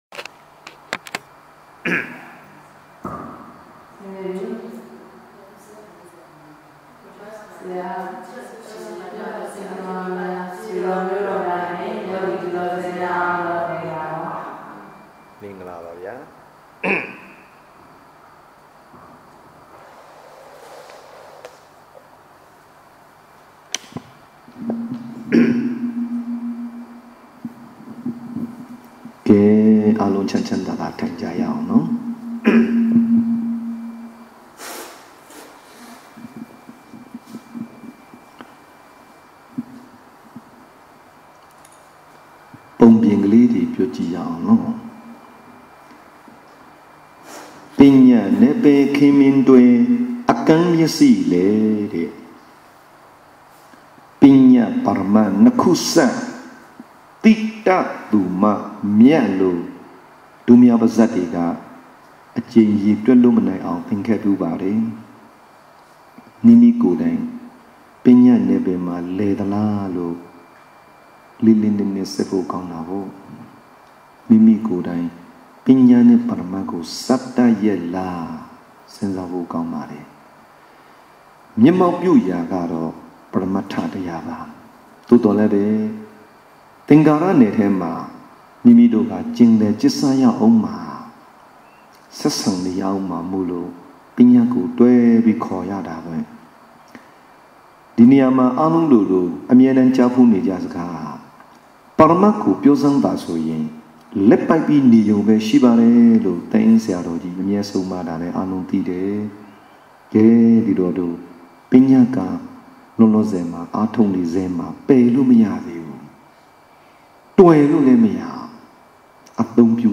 ၂၀၁၄ ဒီဇင်ဘာ ၃၁ ရက်နေ့ တွင် လှည်းကူး ငါးဆူတောင်ရှိ သန့်တည်ပွား မြင့်မြတ်အလင်း ရိပ်သာ ၌ ထာဝရယောဂီ များ အား ဆုံးမဟောကြားထားသော